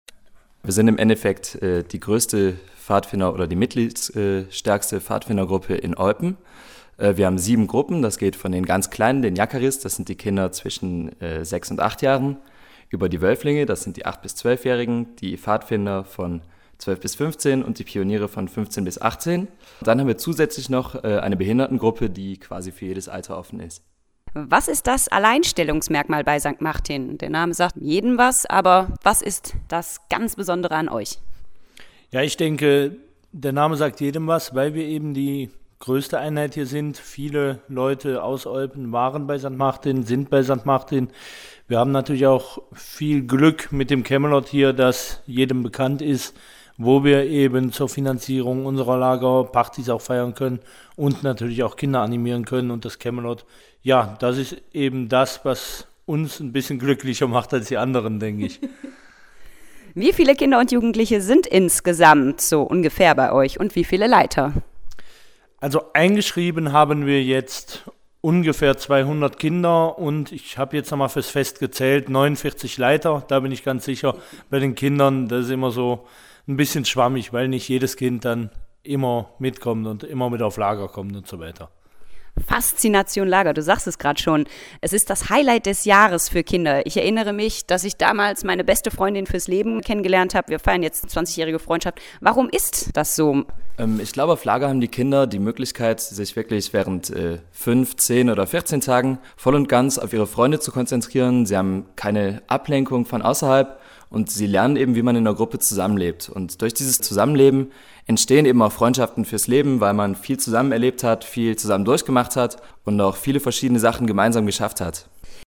einen kleinen Plausch im Camelot am Eupener Kehrweg gehalten